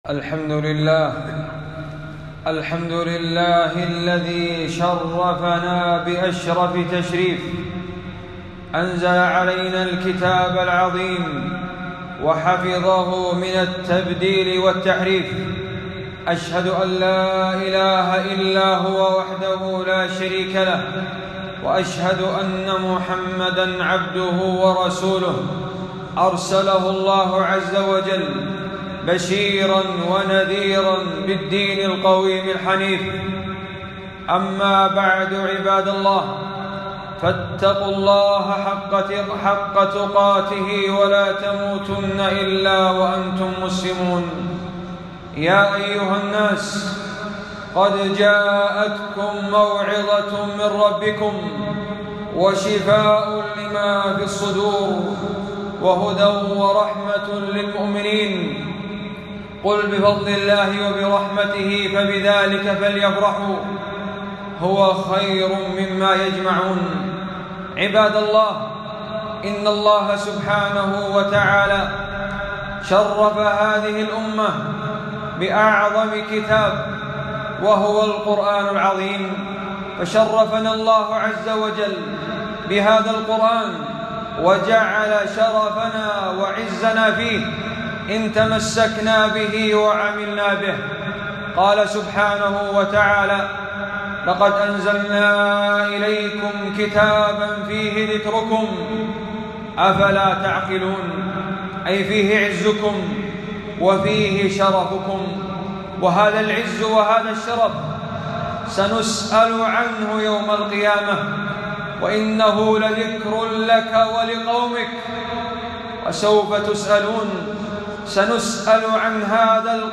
خطبة - فضل القرآن والعناية به